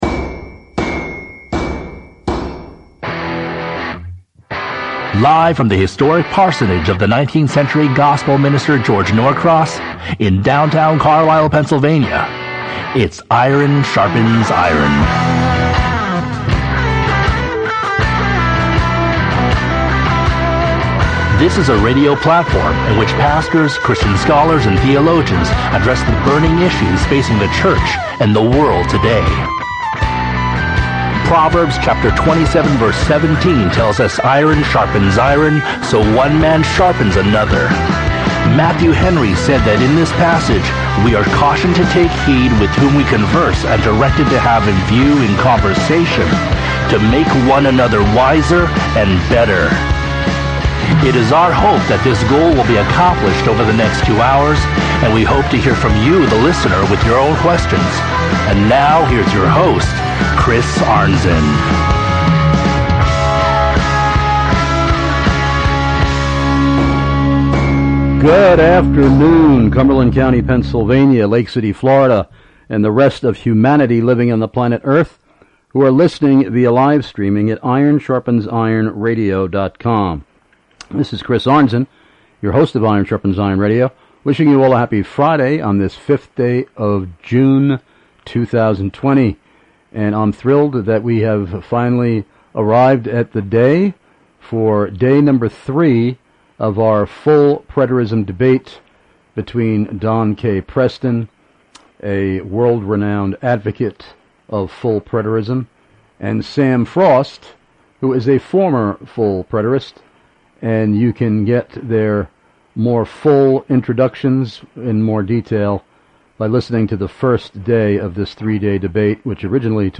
IRON SHARPENS IRON Radio’s DAY #3 of DEBATE on “FULL PRETERISM” TODAY!!: FRIDAY, JUNE 5th, 4-6pm EDT: AUDIENCE Q & A!!!